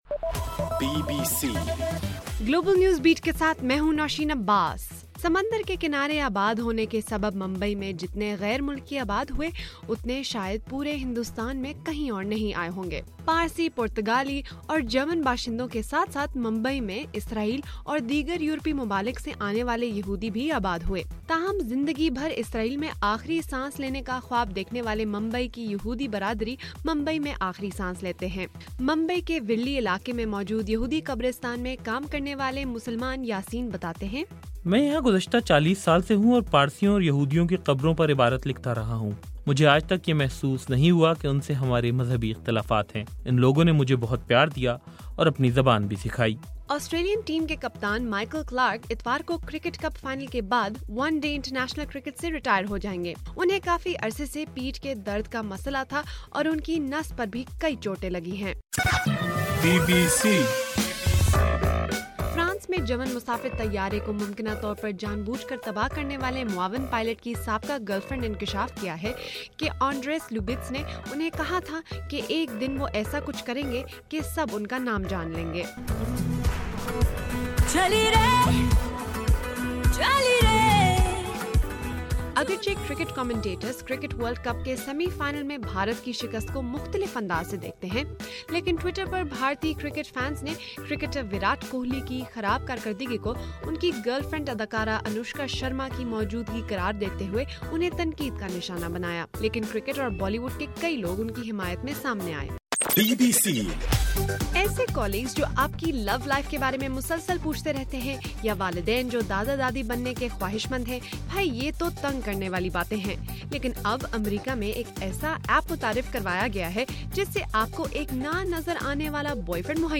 مارچ 28: رات 11 بجے کا گلوبل نیوز بیٹ بُلیٹن